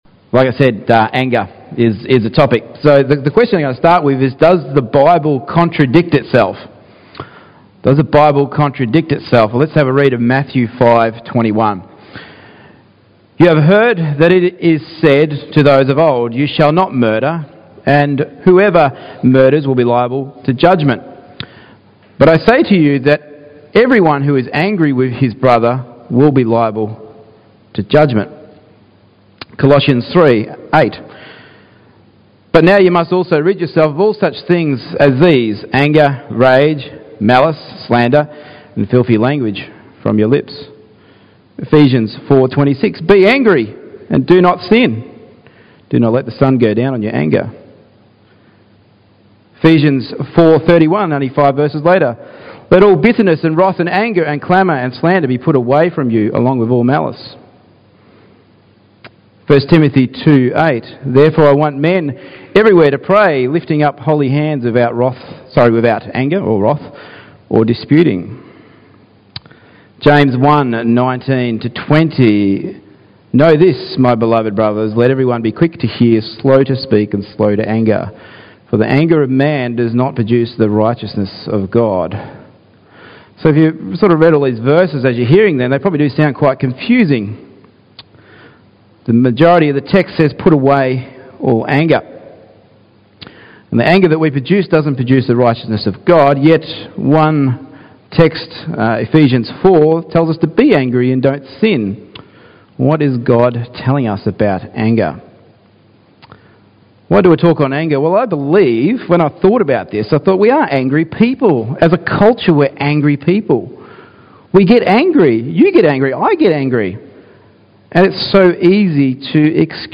Service Type: Morning Gatherings
AngerSermon2016.MP3